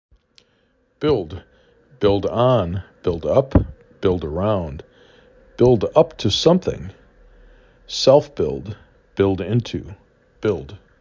5 Letters, 1 Syllable
b i l d